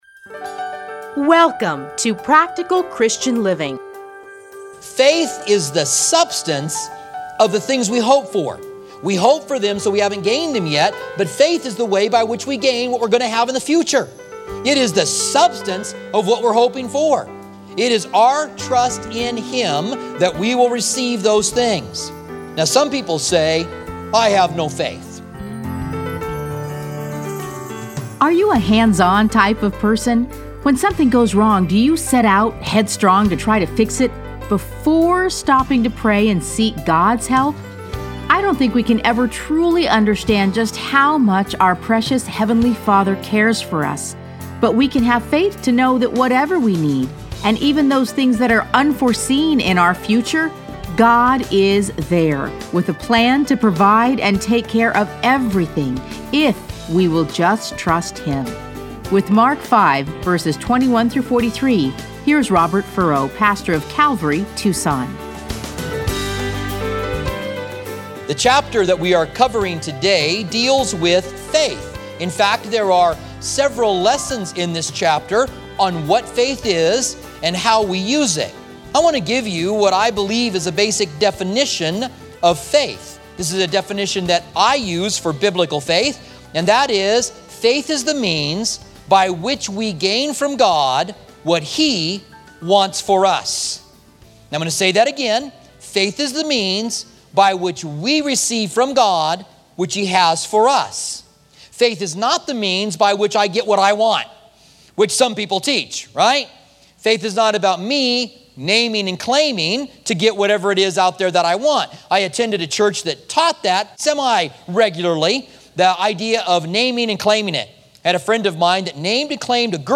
Listen to a teaching from Mark 5:21-43.